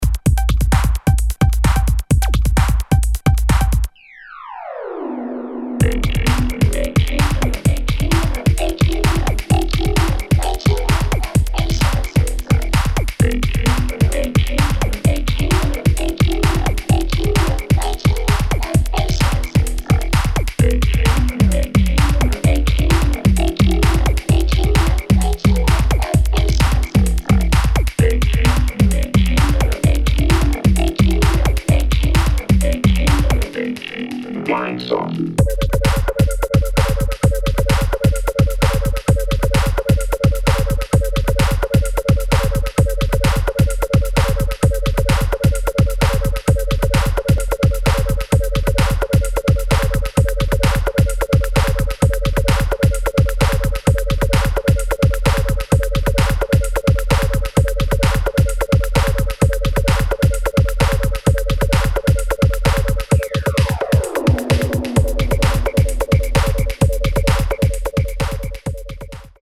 [ UK GARAGE | BASS ]